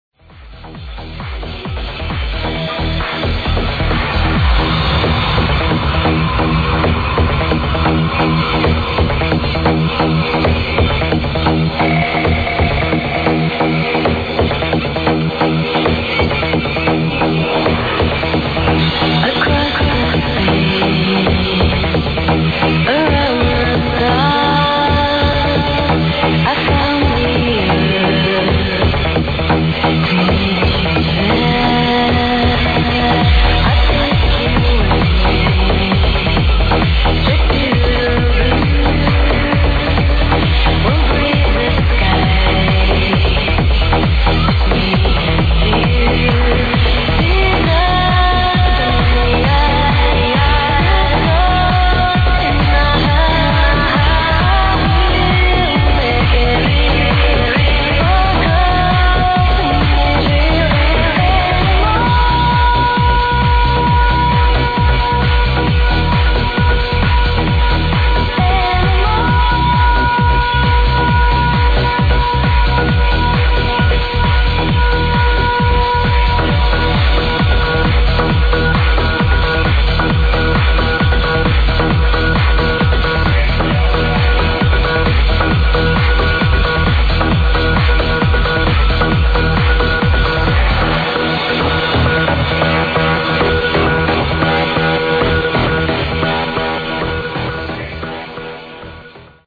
Future Vocal Tune Of The Year!!!